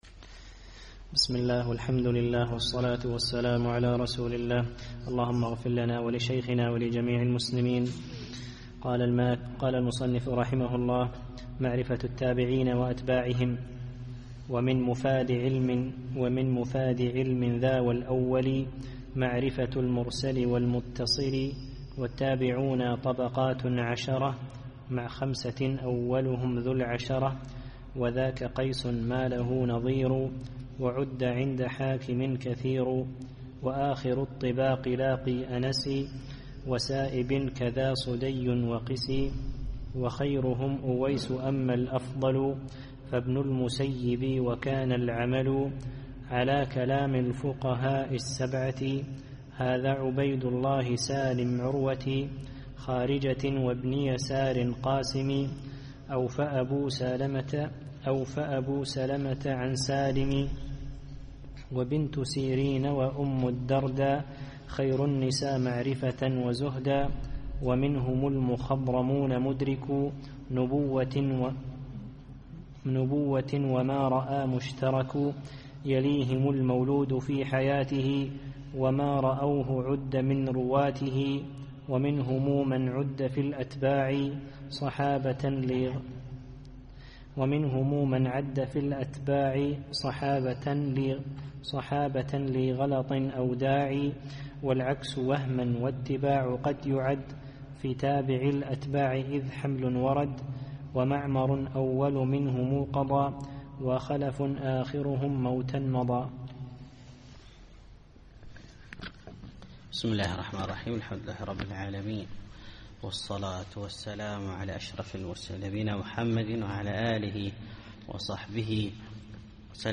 الدرس الثاني والثلاثون